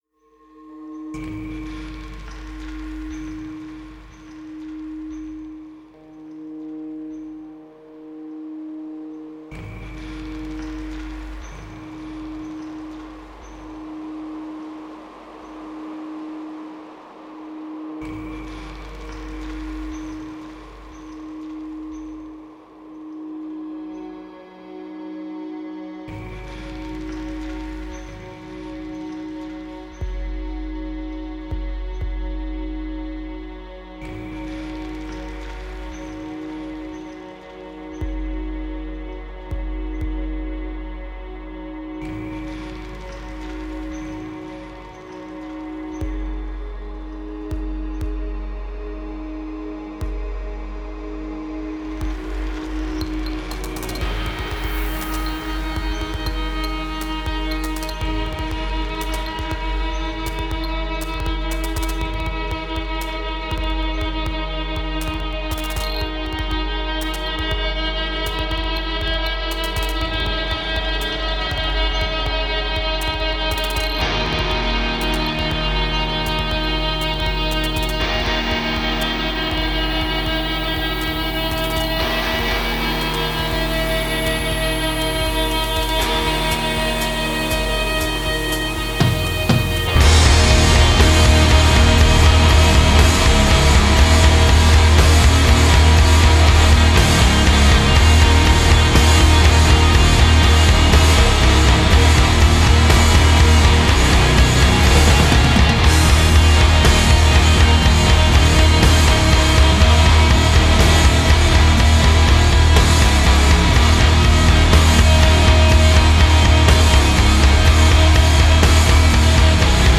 post hardcore
Doom | Metal | Post hardcore | Post metal